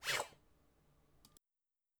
Effects_r1_Eat.wav